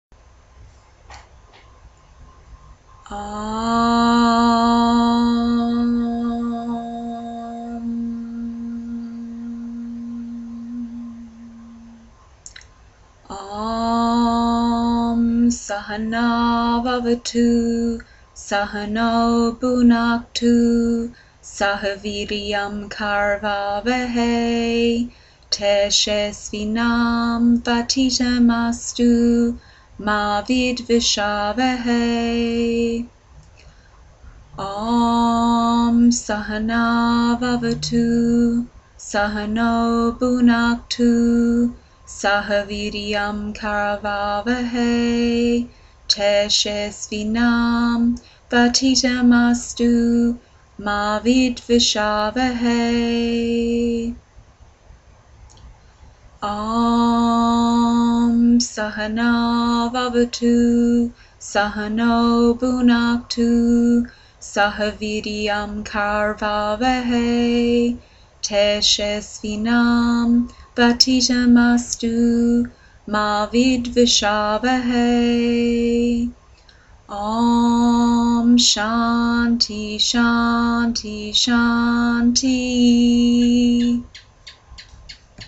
Mantra
The passages that we chant at yogaphysio are short passages taken from ancient philosophical texts called the Upanishads.